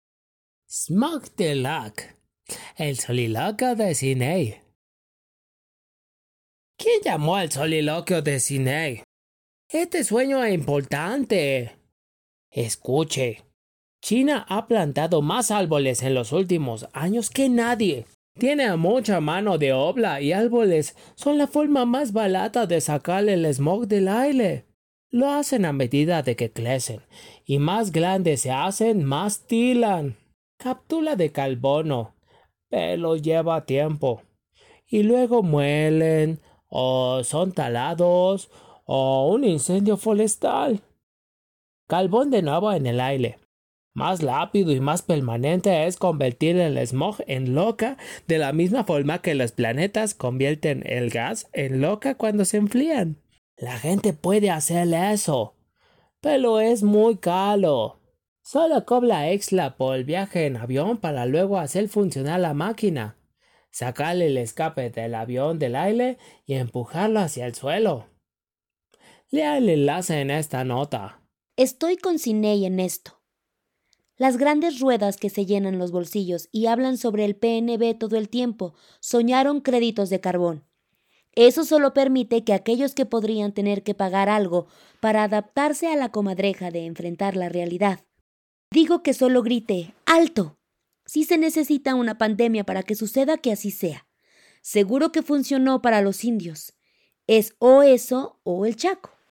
Smog to Rock - El soliloquio de Sine Smog to Rock – Sine’s soliloquy